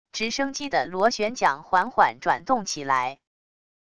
直升机的螺旋桨缓缓转动起來wav音频